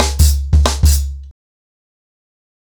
TrackBack-90BPM.9.wav